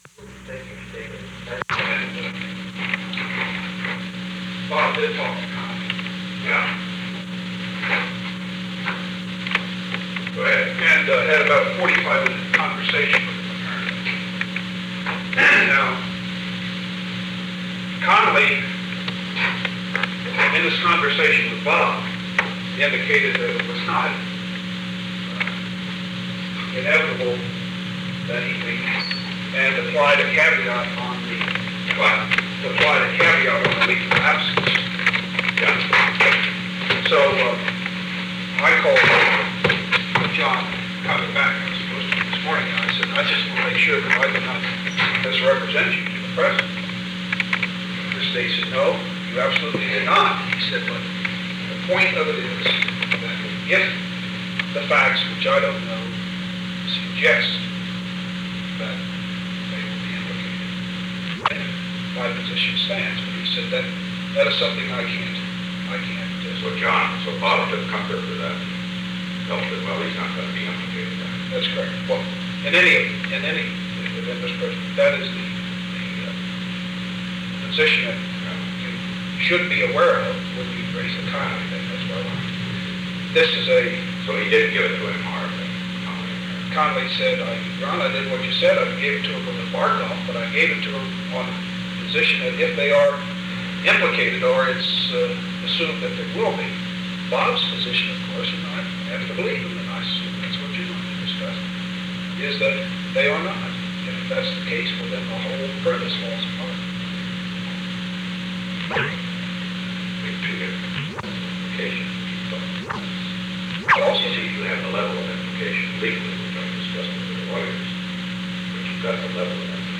Secret White House Tapes
Conversation No. 430-1
Location: Executive Office Building
The President met with Ronald L. Ziegler.